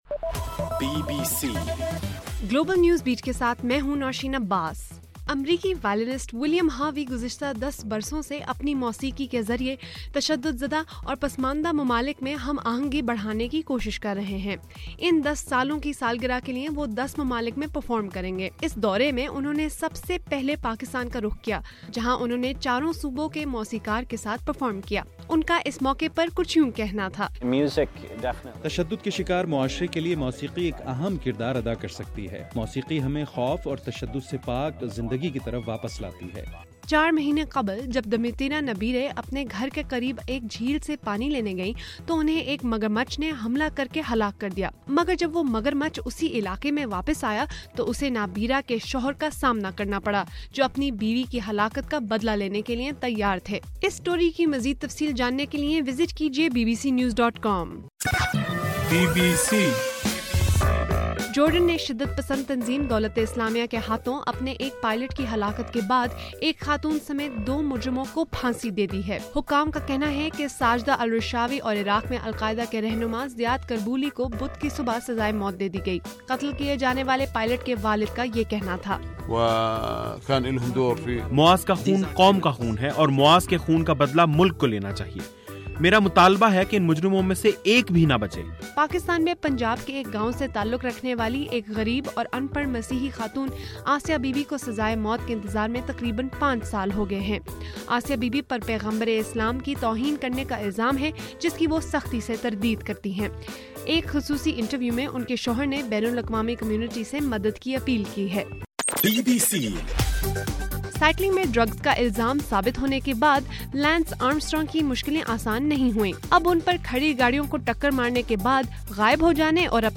فروری 5: صبح 1 بجے کا گلوبل نیوز بیٹ بُلیٹن